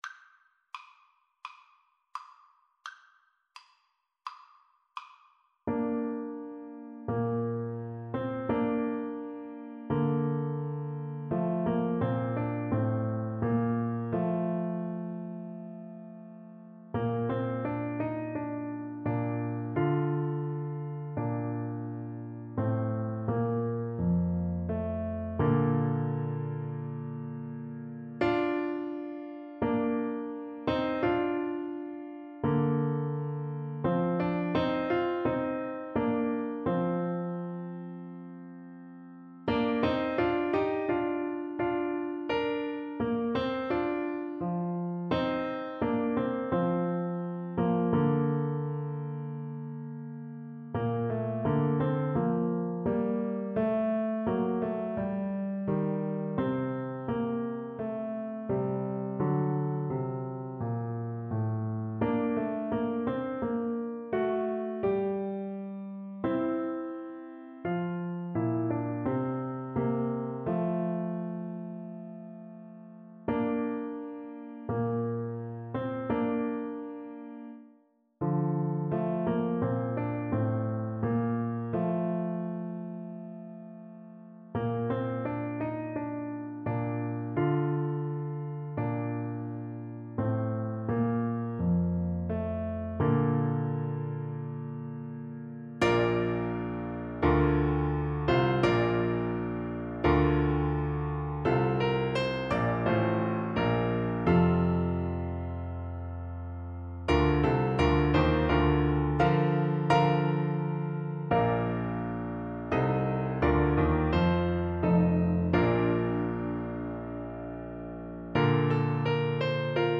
Bassoon
4/4 (View more 4/4 Music)
Bb major (Sounding Pitch) (View more Bb major Music for Bassoon )
Moderato (=120)
Classical (View more Classical Bassoon Music)